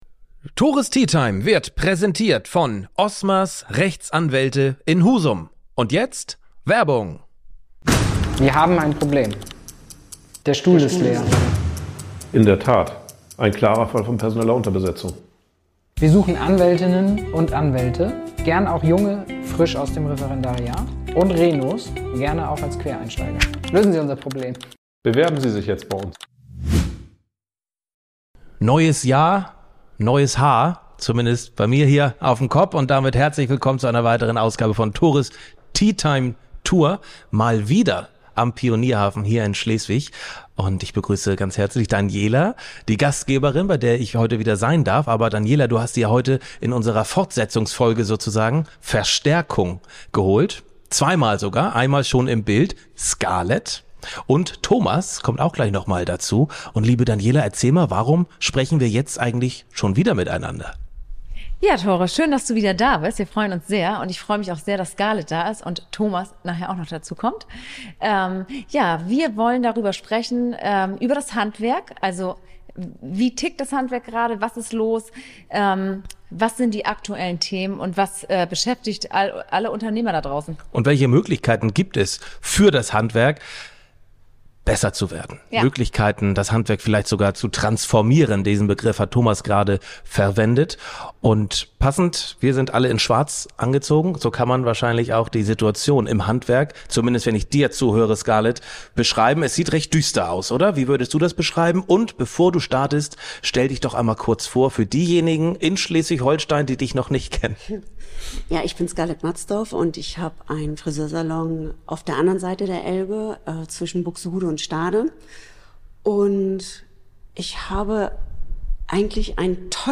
Es wird ein intensives Vierer-Gespräch: Ich als Moderator im Austausch mit drei Friseurpersönlichkeiten, die ihren Beruf nicht nur ausüben, sondern l(i)eben.